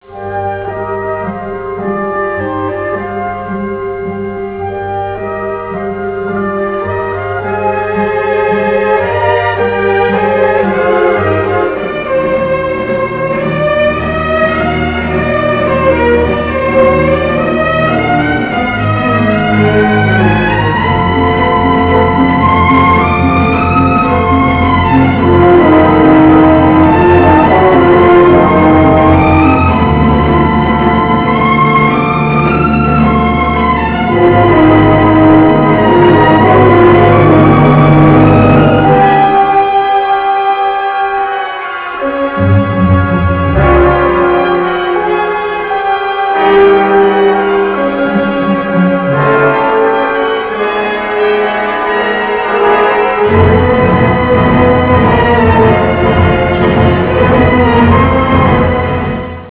Track Music
Colonna sonora